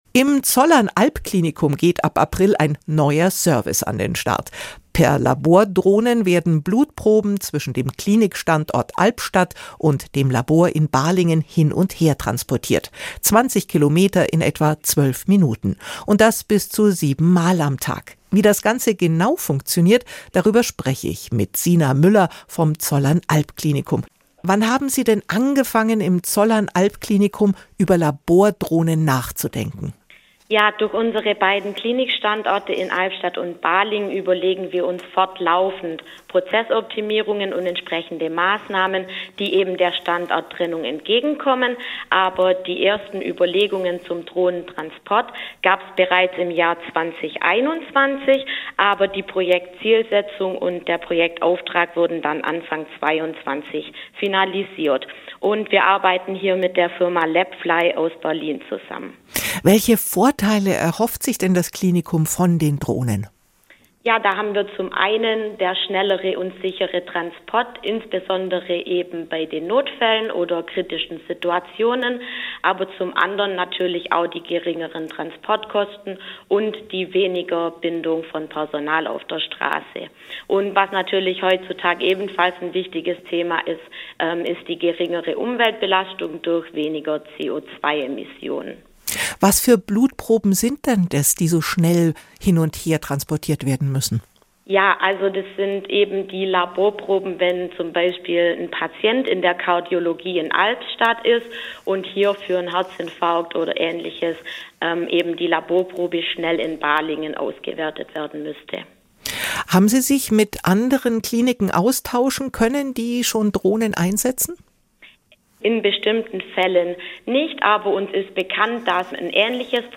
"SWR Aktuell Im Gespräch" - das sind Interviews mit Menschen, die etwas zu sagen haben.